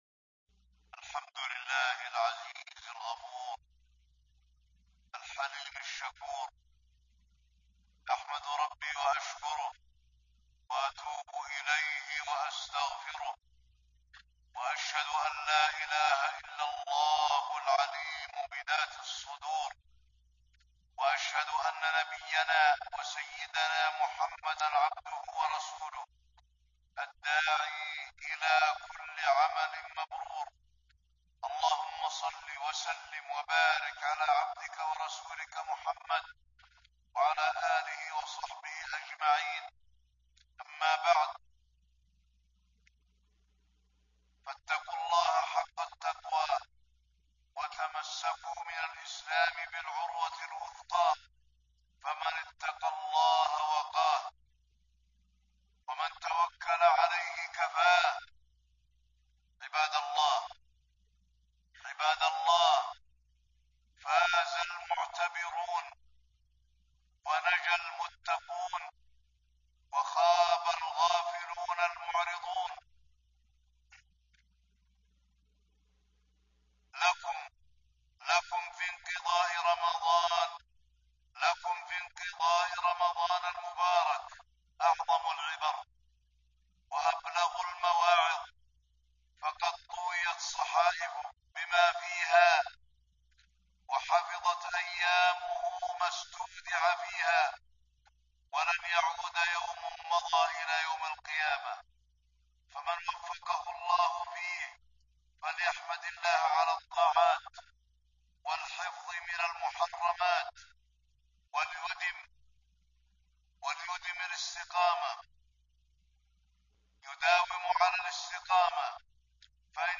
تاريخ النشر ٥ شوال ١٤٣٥ هـ المكان: المسجد النبوي الشيخ: فضيلة الشيخ د. علي بن عبدالرحمن الحذيفي فضيلة الشيخ د. علي بن عبدالرحمن الحذيفي فضل الاستقامة على الطاعة